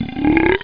burp.mp3